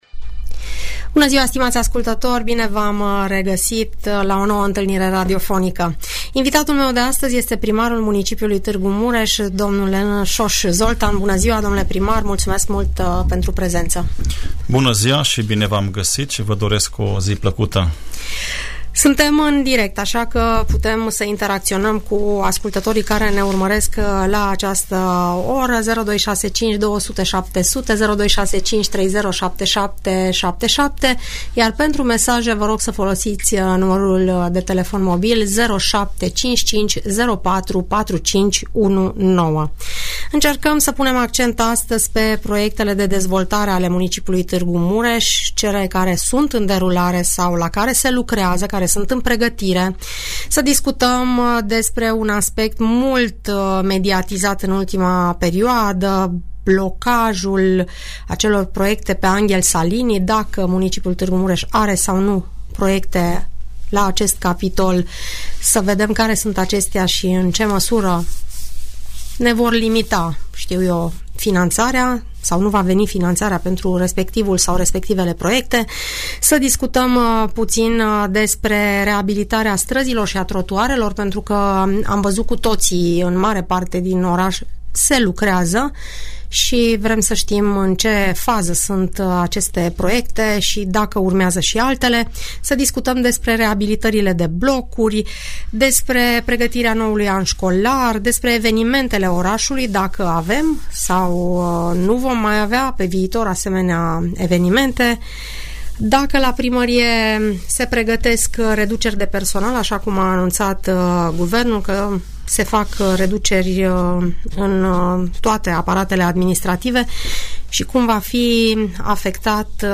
Audiență radio cu primarul municipiului Tg. Mureș, dl Soós Zoltán